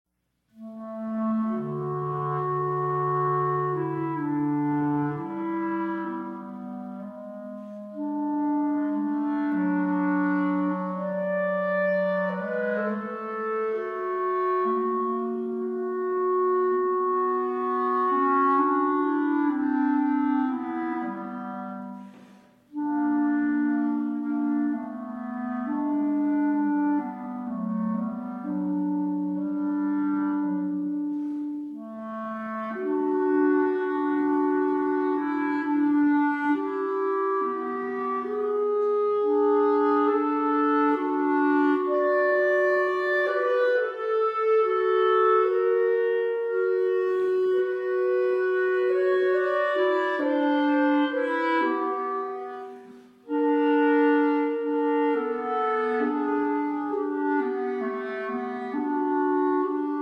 Bb clarinets
Traditional: African-American Spiritual